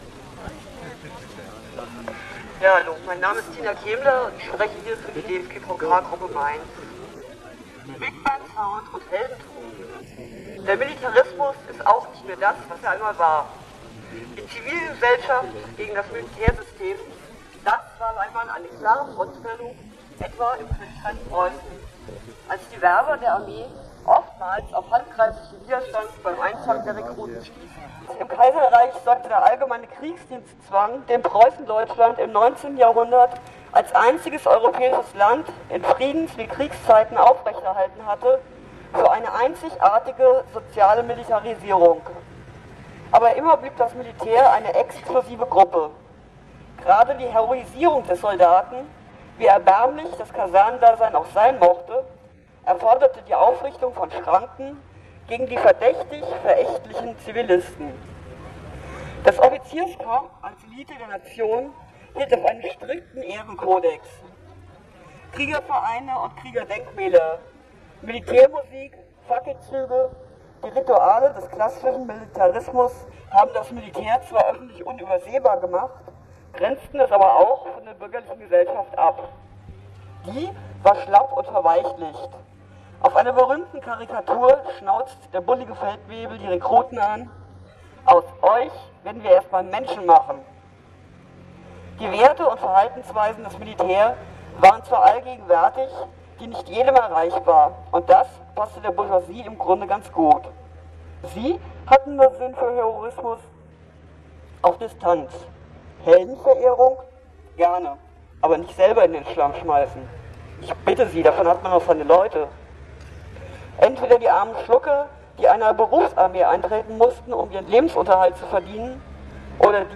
Nachlese der Demo gegen die Militärverherrlichung